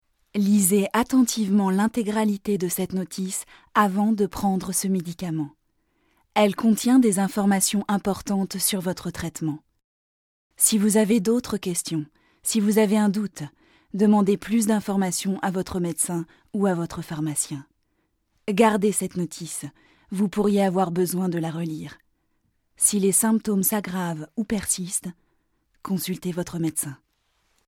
comédienne 30 ans, voix médium.
Sprechprobe: Industrie (Muttersprache):